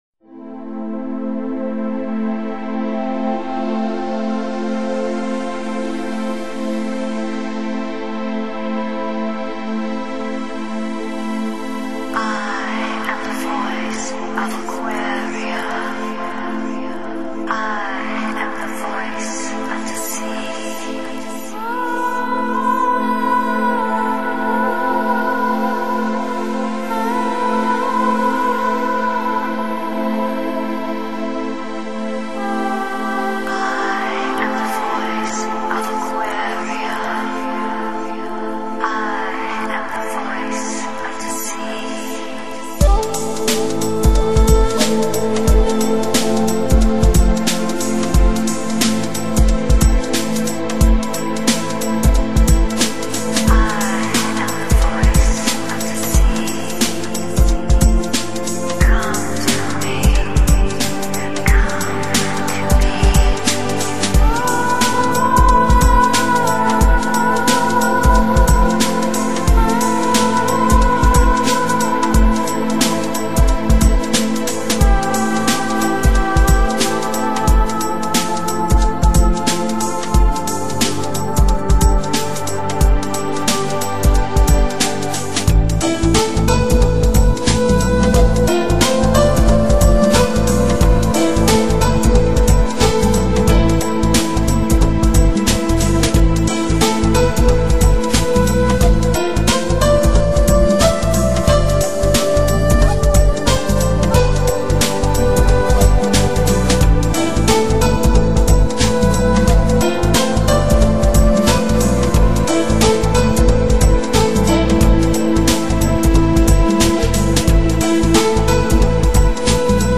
是一首长达九分钟的超豪华序曲，
乐曲开始时大气十足，进行中慢慢而转向RB/pop风格《Through
（低品质）